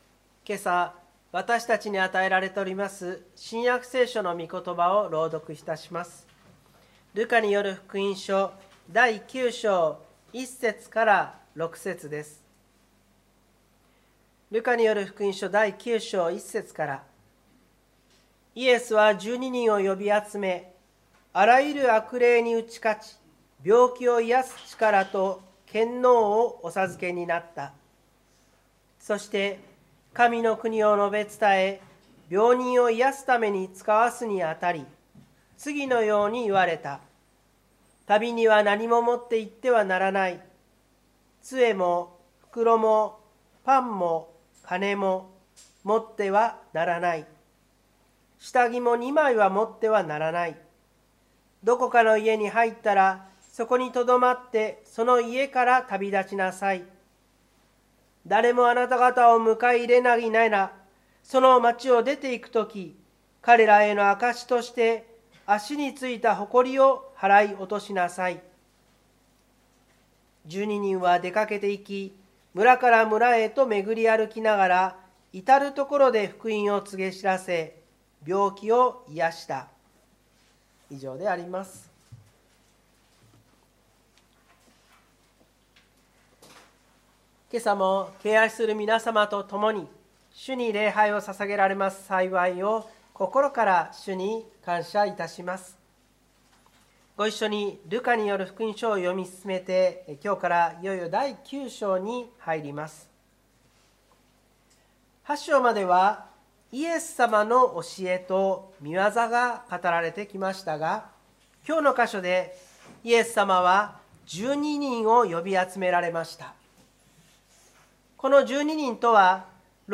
湖北台教会の礼拝説教アーカイブ。